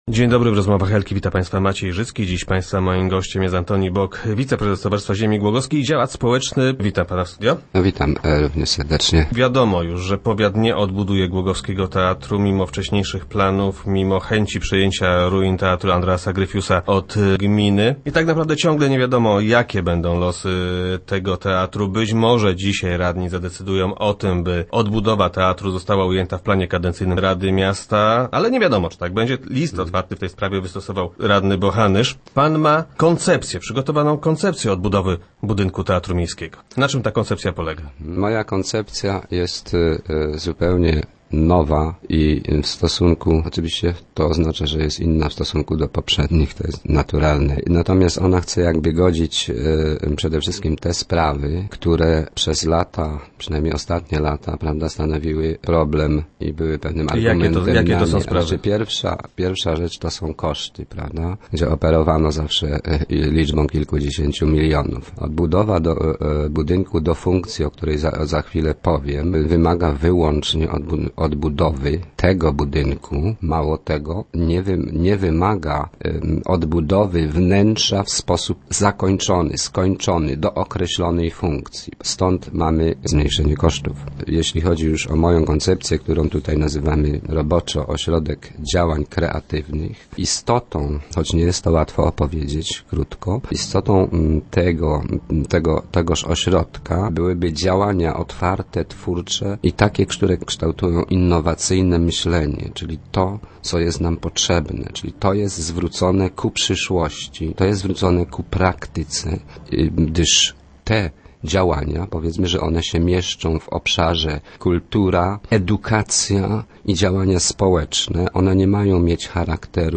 - Istotą tego ośrodka byłyby otwarte działania twórcze. Takie, które kształtują innowacyjne myślenie. Ośrodek nastawiony byłby więc na przyszłość, na praktykę i na działania, które mieściłyby się w zakresie kultury, edukacji i działań społecznych i to takich , które angażowałyby lokalną społeczność - tłumaczył na radiowej antenie gość Rozmów Elki.